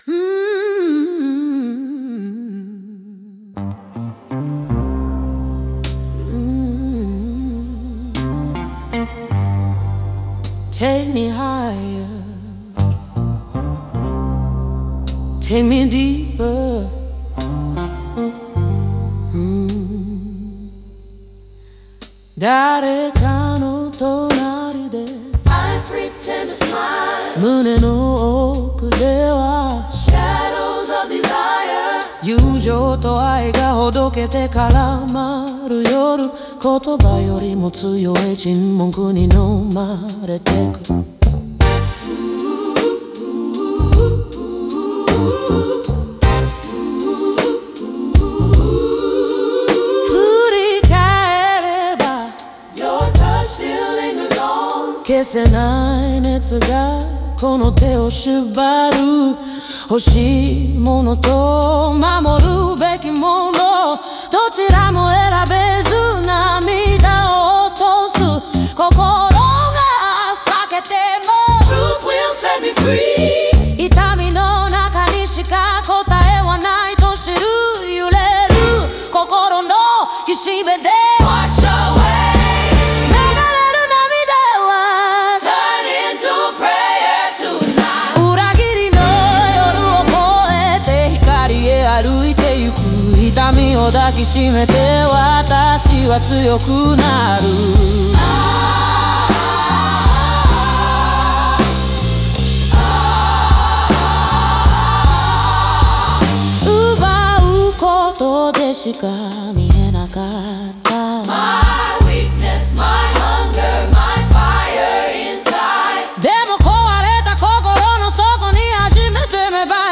オリジナル曲♪
ホーンやオルガンが支えるゴスペル風のアレンジは、心の浄化や救済を象徴し、聴く者を魂の解放へ導きます。